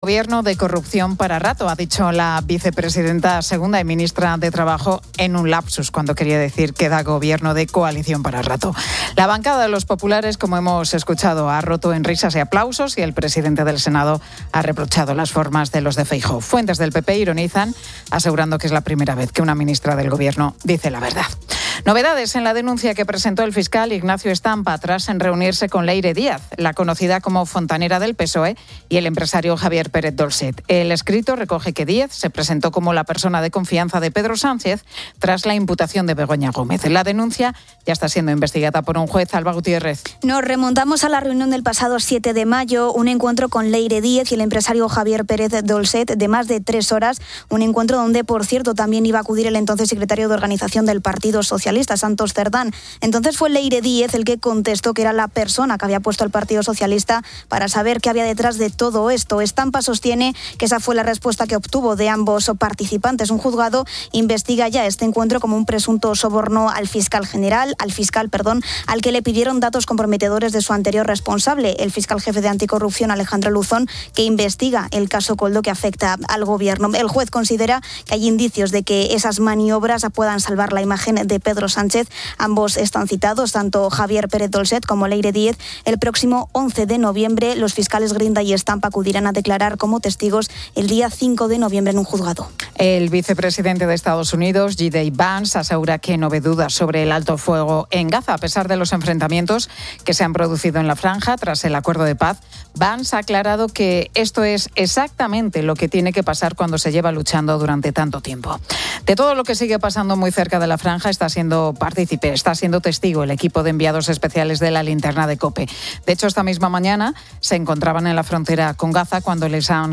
En Gaza, el vicepresidente de EEUU, JD Vance, aborda el alto el fuego. Los enviados especiales de COPE informan desde la frontera, bajo disparos. En deportes, se disputan partidos de Champions League: Barcelona-Olympiacos, Atlético de Madrid-Arsenal y Villarreal-Manchester City.